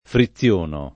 friziono [ fri ZZL1 no ]